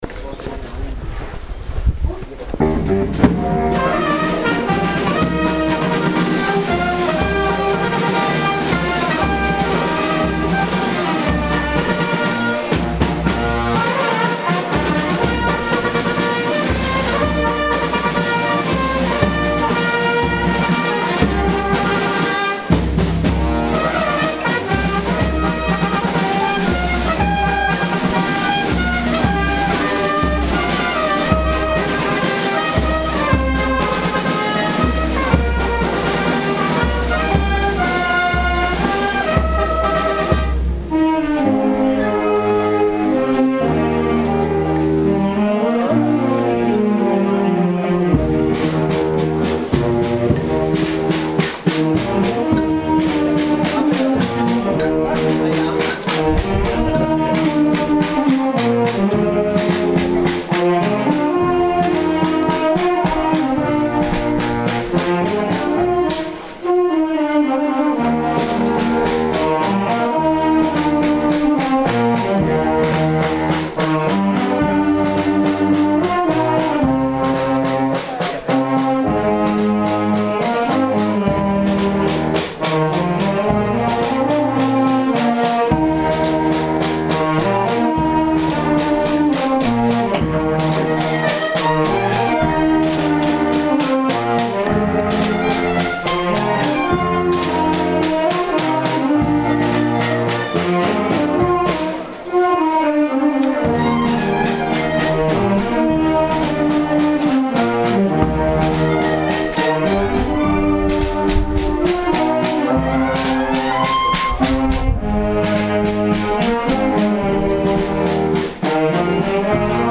Marcetta Bandistica
La marcetta appena proposta invece, è stata registrata a partire da via Dietro gli Orti, per arrivare in piazza Riccardo, fra un mistero e l’altro, recitati dal sacerdote, ma poco seguiti da gente che vociava e parlava dei fatti propri con chi era vicino.